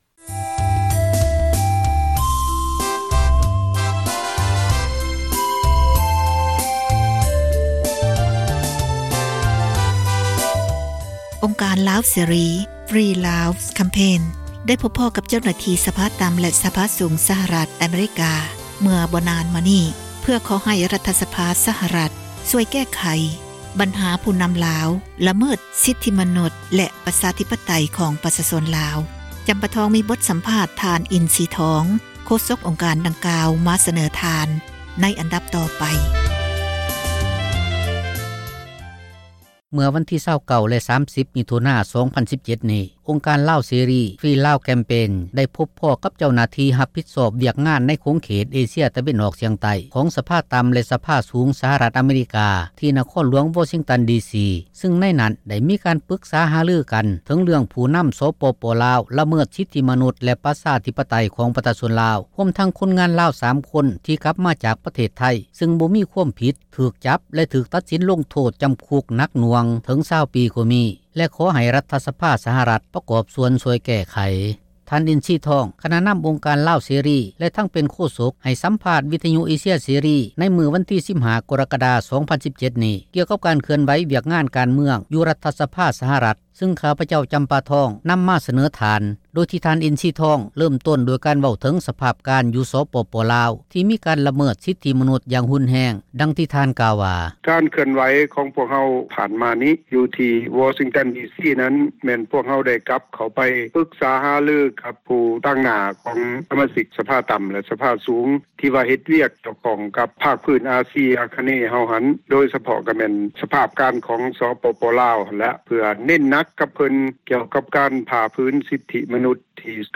ໃຫ້ສໍາພາດ